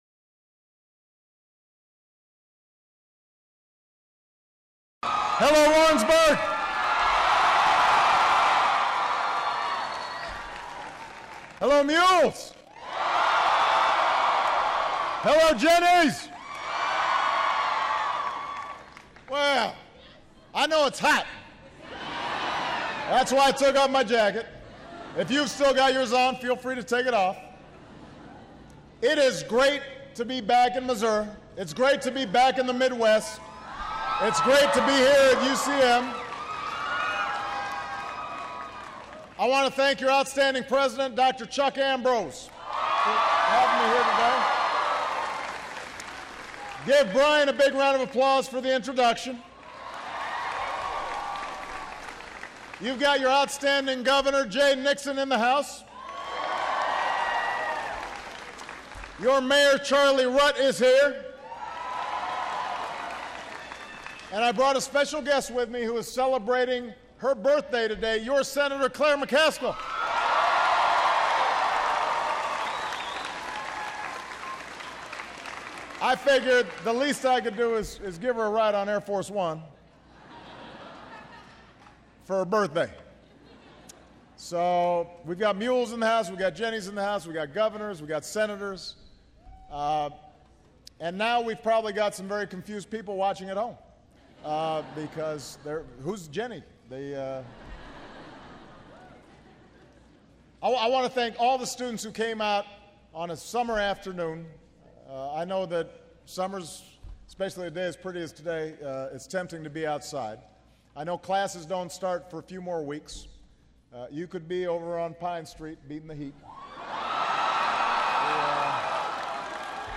July 24, 2013: Remarks on Education and the Economy